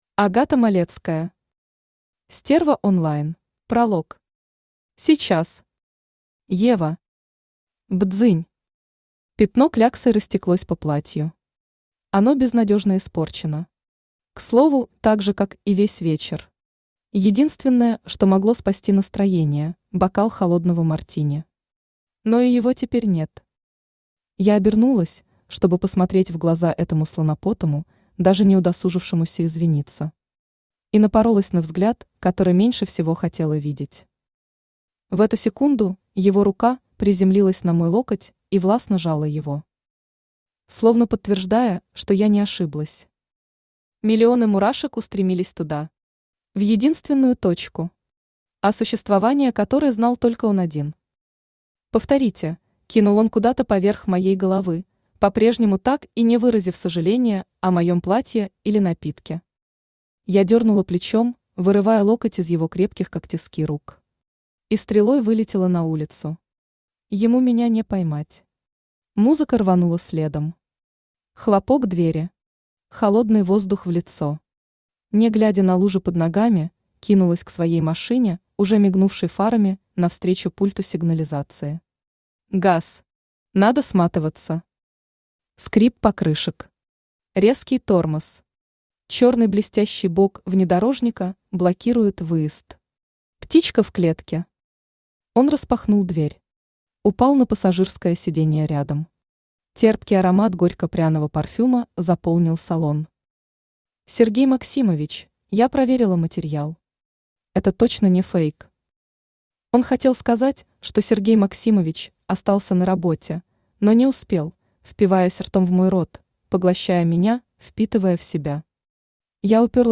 Аудиокнига Стерва онлайн | Библиотека аудиокниг
Aудиокнига Стерва онлайн Автор Агата Малецкая Читает аудиокнигу Авточтец ЛитРес.